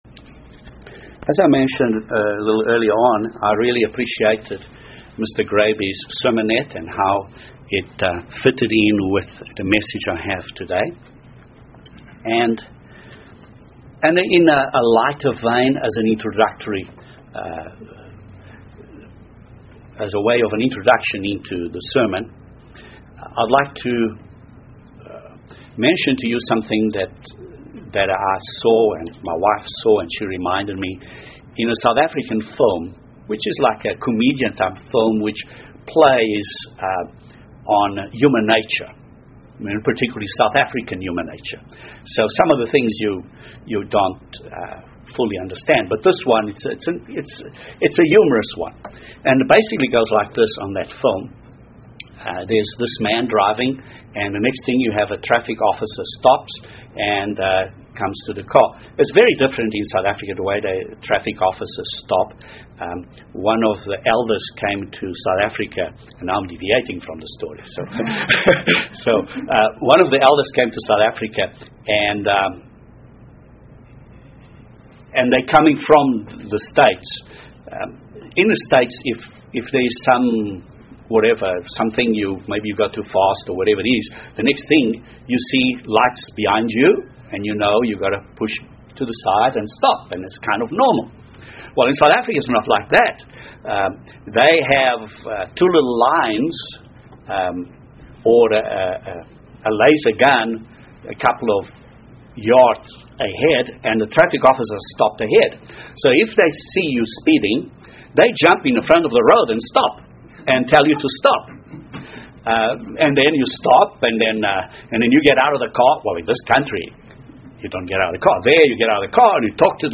Jesus Christ will save the world from destruction by establishing the rule of the Kingdom of God on earth. This sermon gives more details about these 3 points as we approach the day of Trumpets.